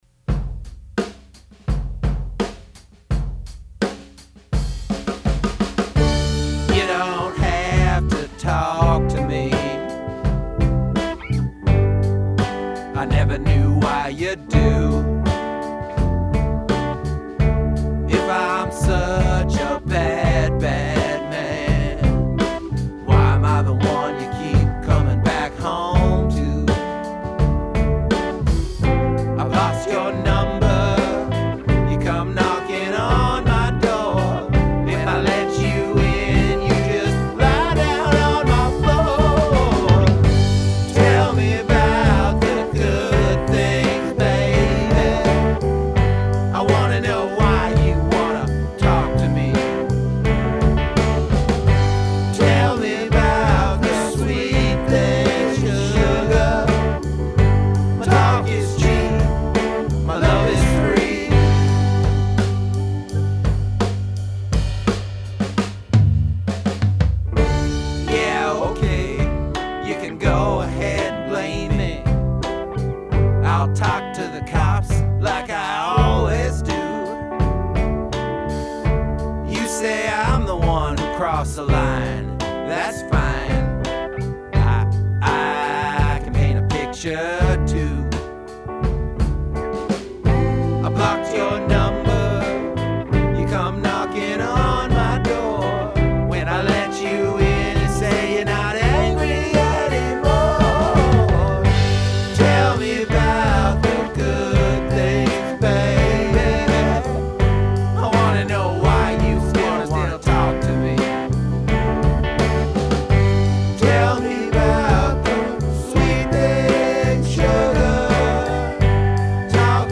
Studio rough bounces:
Why?: The vocal I provided in studio is upper middle register, sounds whiny and pleading.